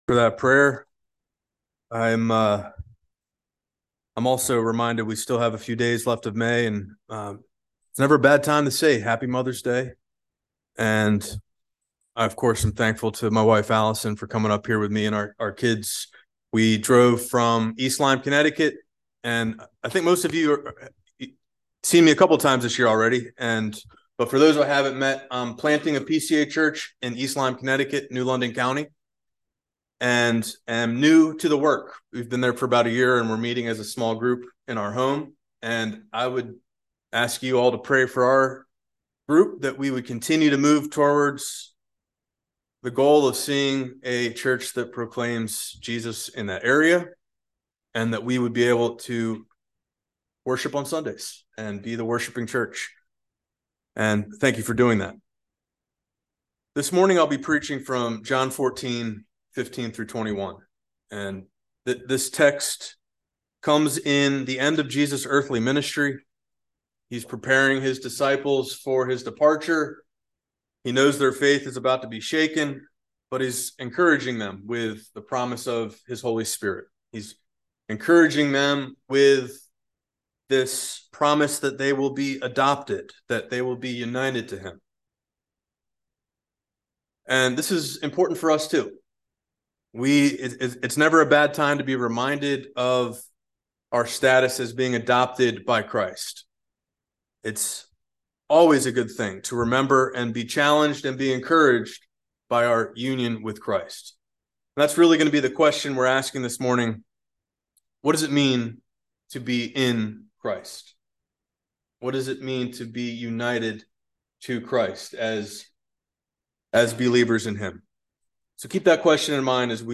by Trinity Presbyterian Church | May 30, 2023 | Sermon